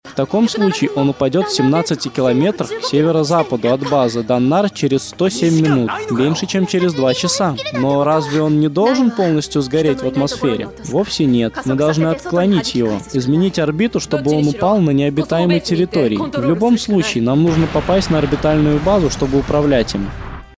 Читает сабы без эмоций, а так все нормально.